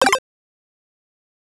8bit_FX_C_03_03.wav